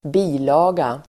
Uttal: [²b'i:la:ga]